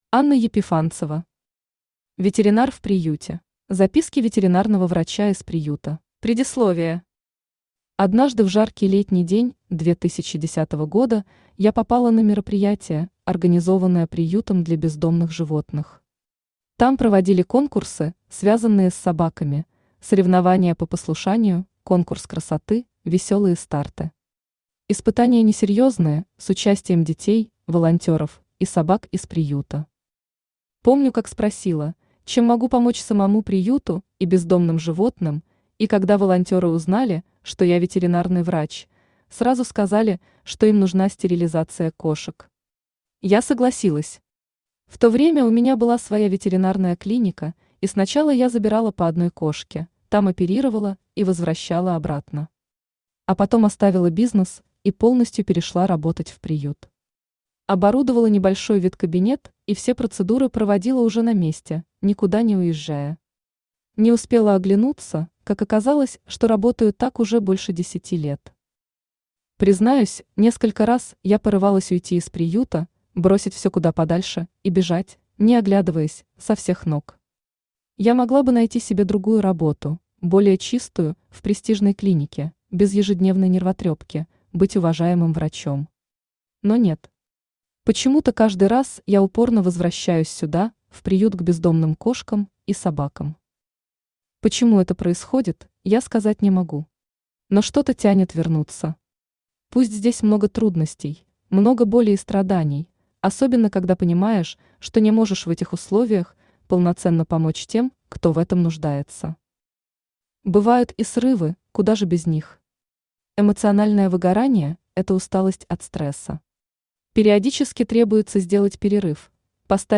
Аудиокнига Ветеринар в приюте | Библиотека аудиокниг